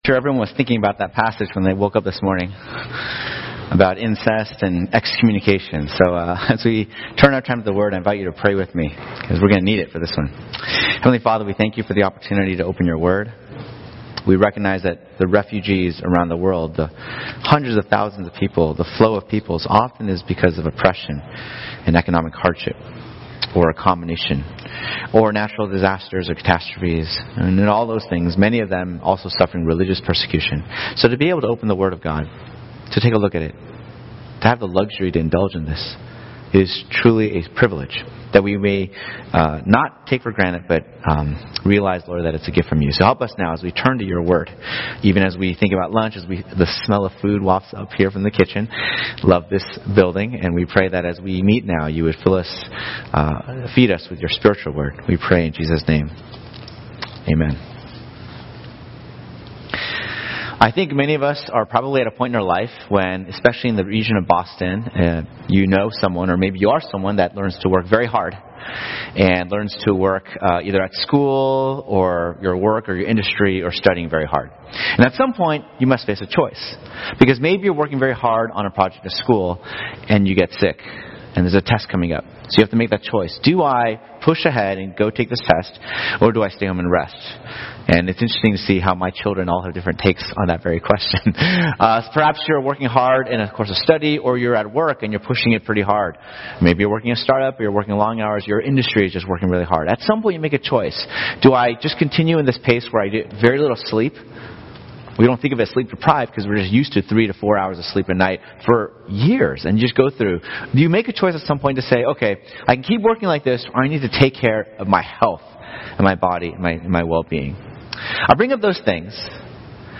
The umbrella term/category for all Sermons from all congregations.